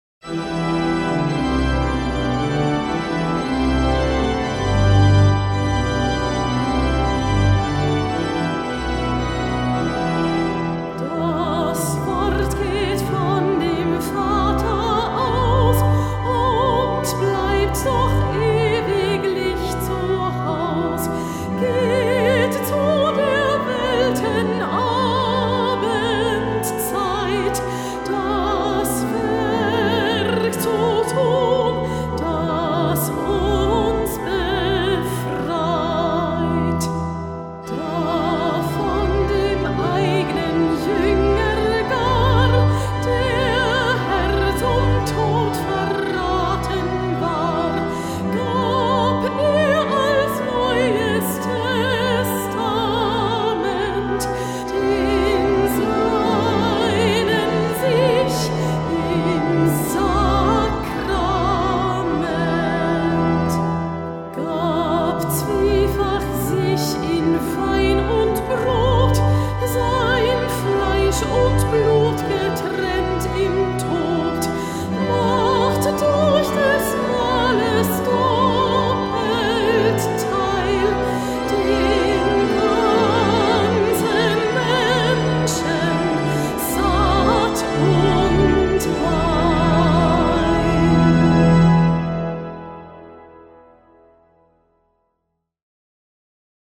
Wochenlied: